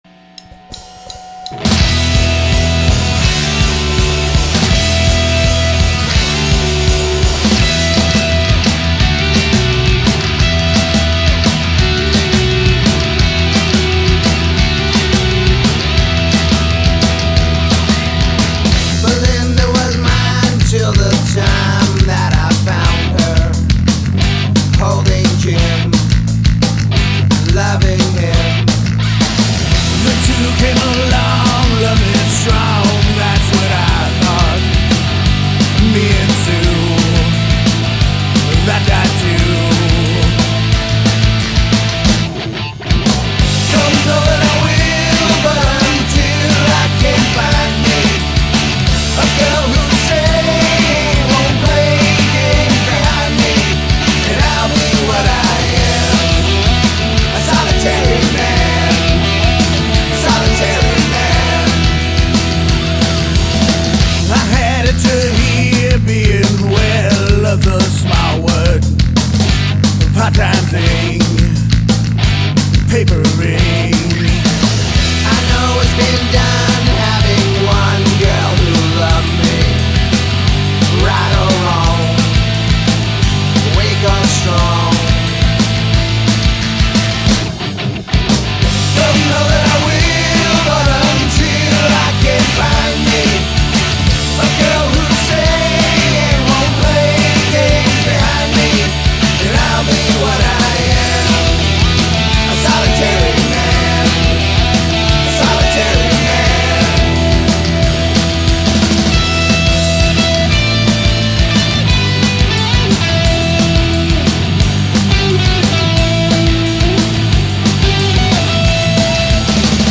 Guitar, Vocal
Drums
Bass
Recorded at Tru-One Studio